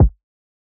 nightcrawler kick.wav